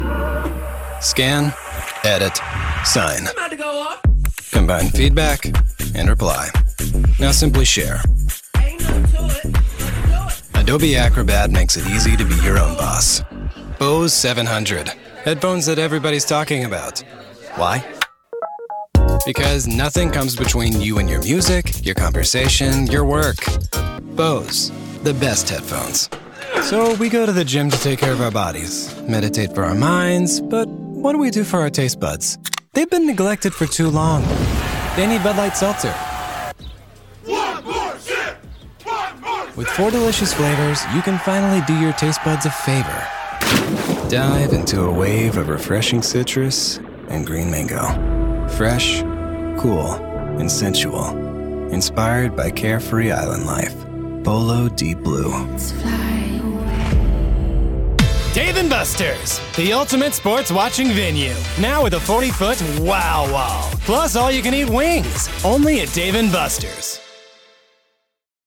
Teenager, Young Adult, Adult, Mature Adult
Has Own Studio
southern us | natural
standard us | natural
COMMERCIAL 💸